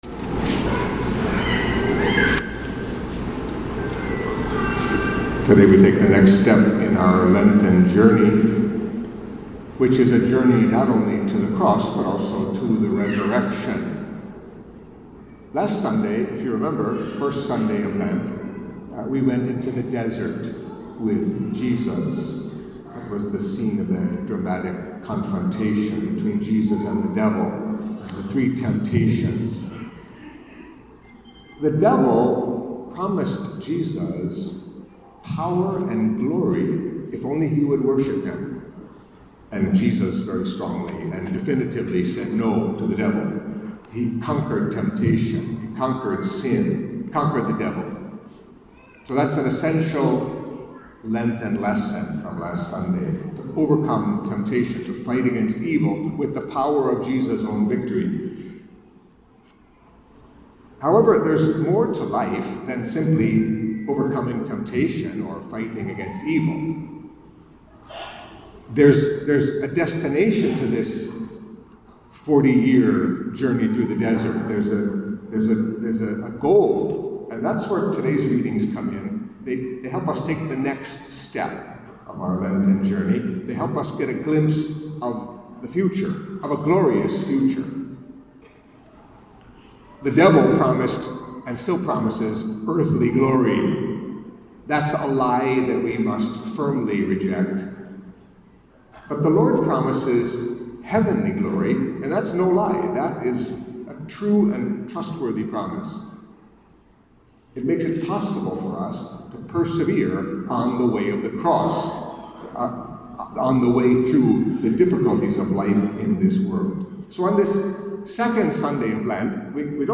Catholic Mass homily for Second Sunday of Lent